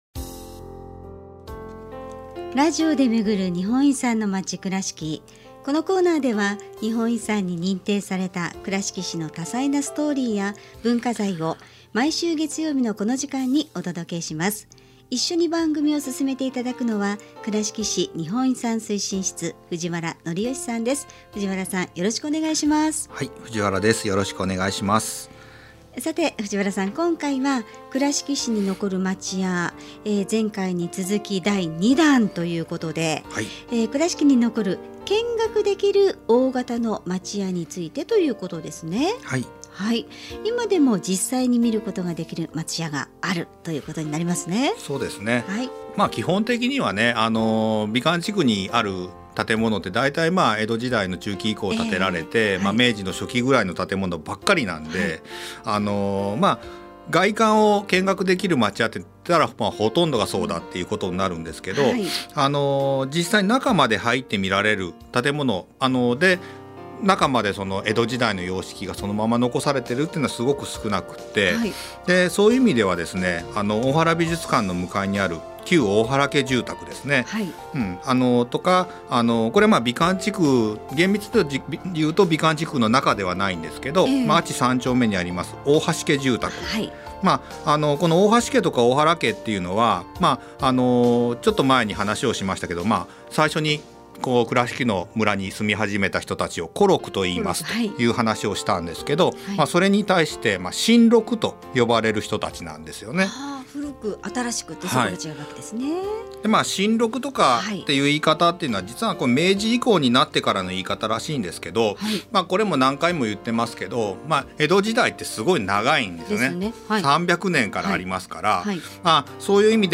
平成31年4月～令和2年3月の間、FMくらしきのワイド番組「モーニングくらしき」内で、倉敷市の日本遺産を紹介するコーナー「ラジオで巡る日本遺産のまち くらしき」を放送しました。